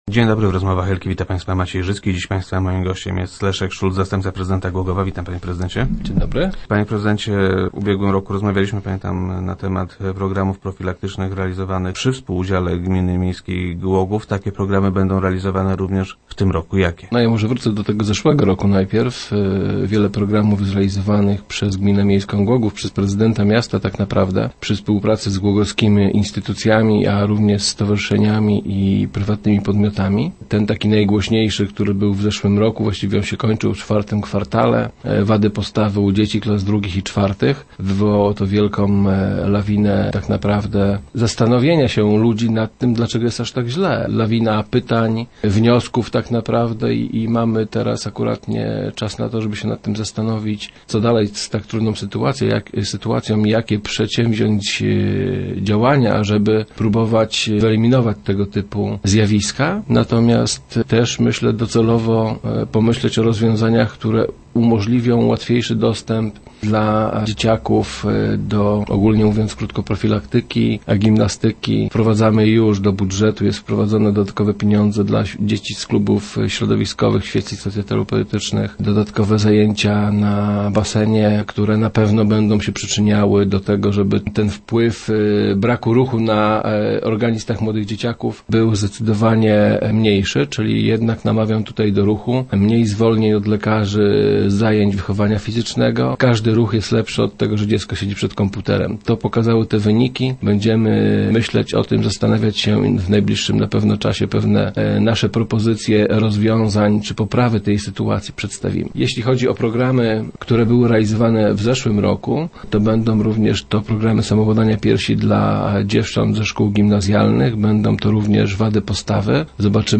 - Będą one realizowane również w roku bieżącym - zapewniał na radiowej antenie Leszek Szulc, zastępca prezydenta Głogowa, który był gościem Rozmów Elki.